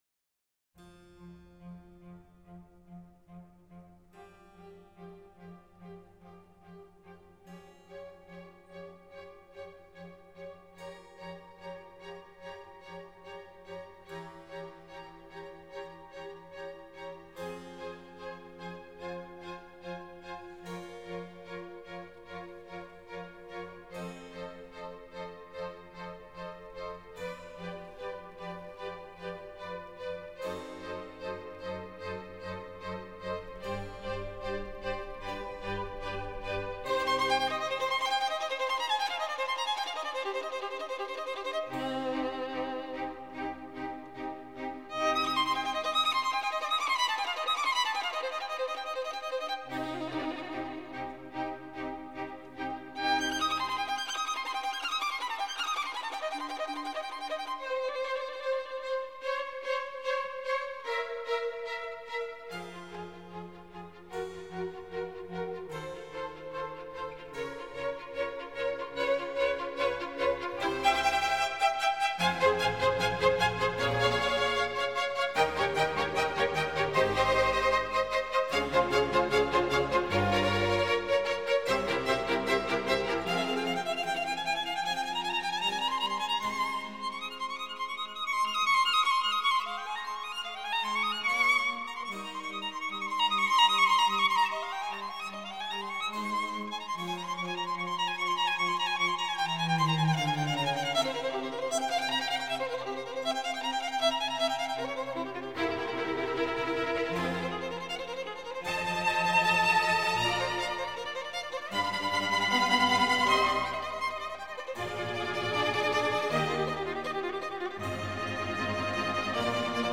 موسیقی بی کلام «زمستان» اثری از ویوالدی